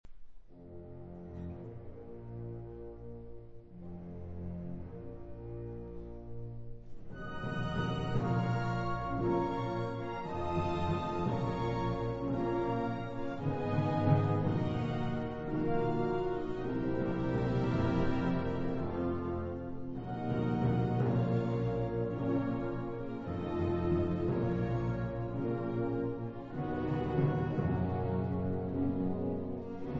baritono
soprane
• registrazione sonora di musica